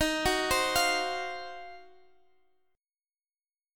Ebm6add9 Chord
Listen to Ebm6add9 strummed